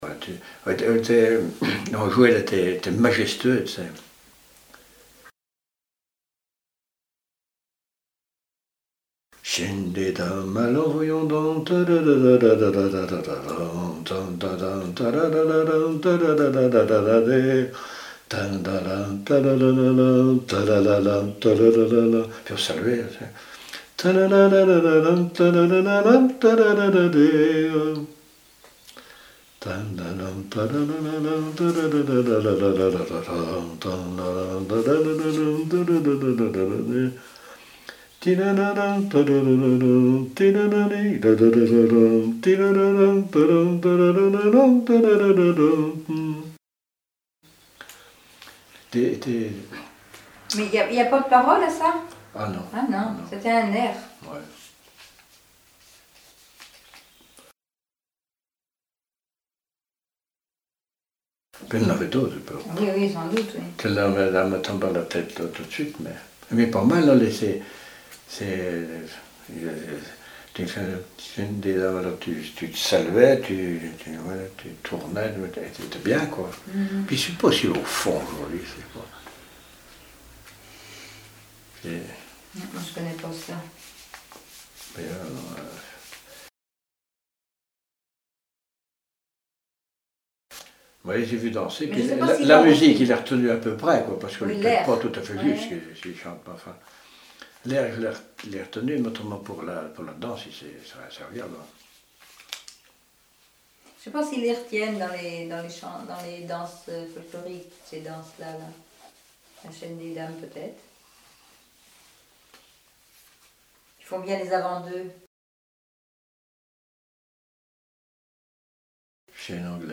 danse : quadrille
Répertoire de chants brefs pour la danse
Pièce musicale inédite